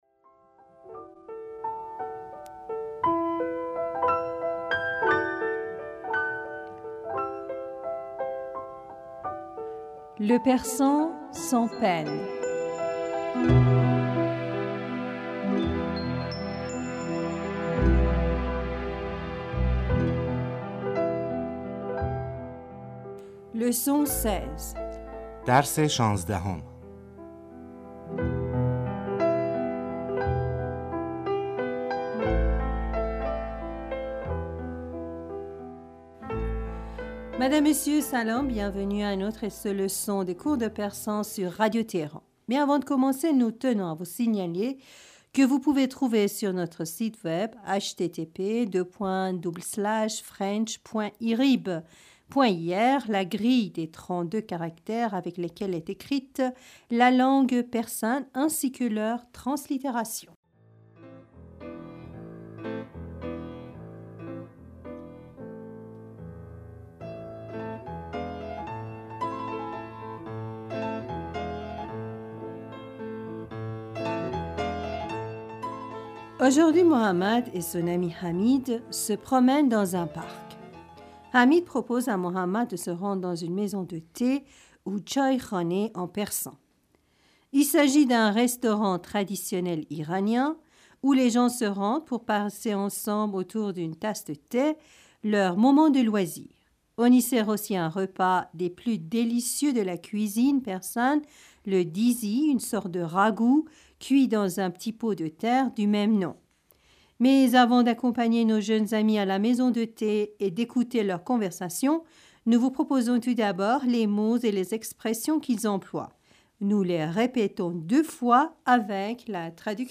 Nous les répétons deux fois, avec la traduction.
Nous répétons chaque phrase avec la traduction.